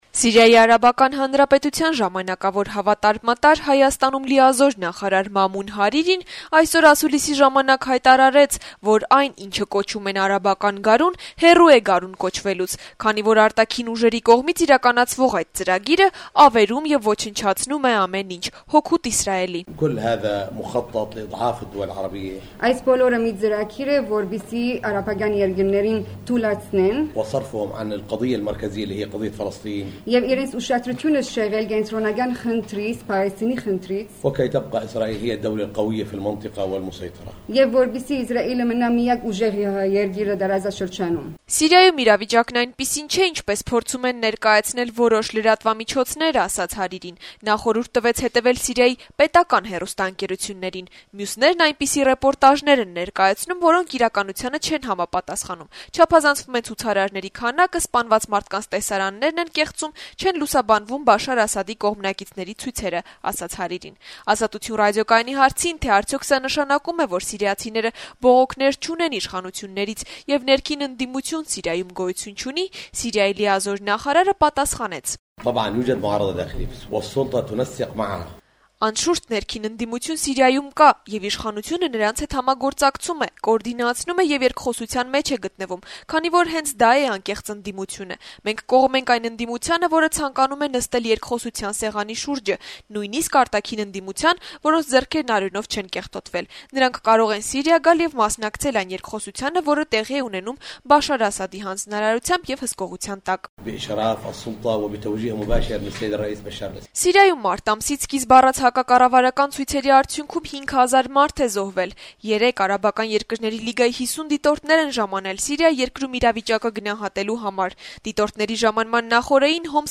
Սիրիայի Արաբական Հանրապետության ժամանակավոր հավատարմատար, Հայաստանում լիազոր նախարար Մամուն Հարիրին այսօր հրավիրած ասուլիսի ժամանակ հայտարարեց, որ այն, ինչն անվանում են «արաբական գարուն», հեռու է գարուն կոչվելուց, քանի այդ` արտաքին ուժերի կողմից իրականացվող ծրագիրն ավերում է, ոչնչացնում ամեն ինչ` հօգուտ Իսրայելի: